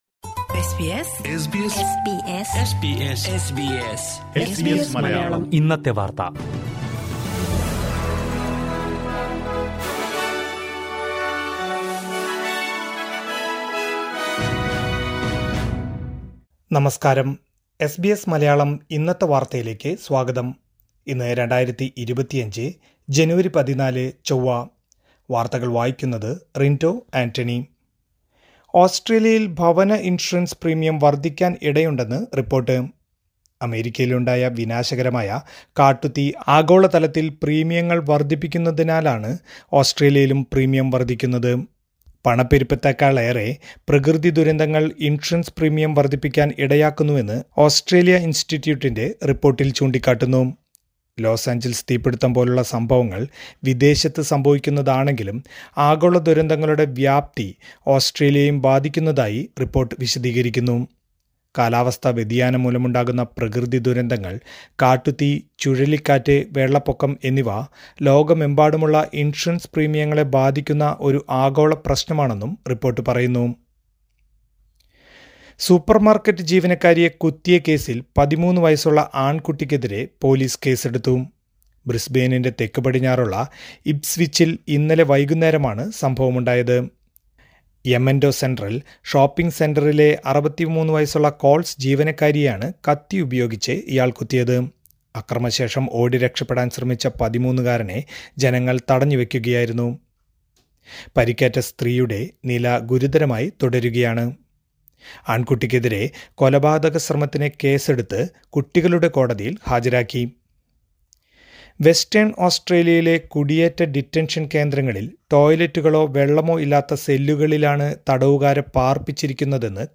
2025 ജനുവരി 14ലെ ഓസ്ട്രേലിയയിലെ ഏറ്റവും പ്രധാന വാർത്തകൾ കേൾക്കാം..